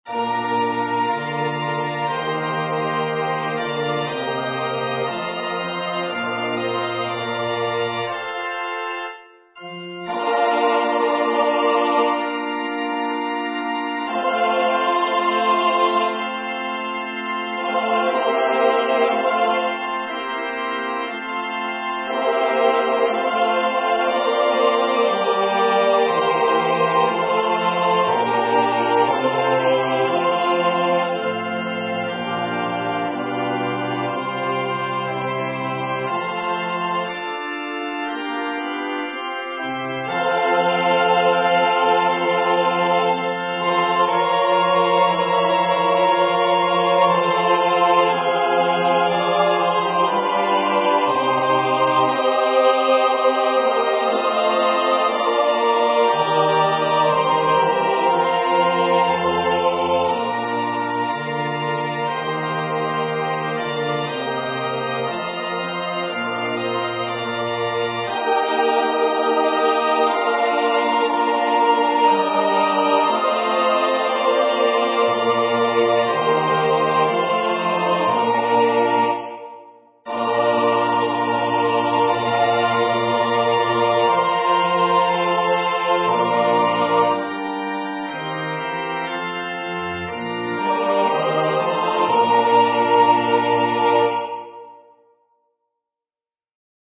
Number of voices: 4vv   Voicing: SATB
Genre: SacredMass
Instruments: Organ